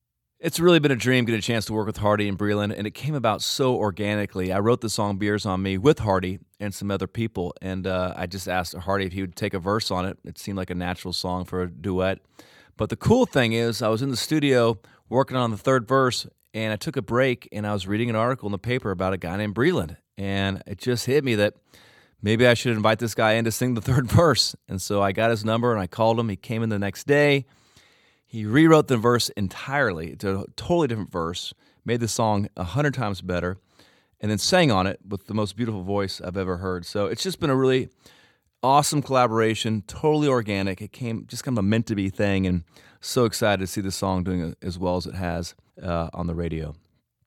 Audio / Dierks Bentley talks about working with Hardy and BRELAND on his latest hit, "Beers On Me."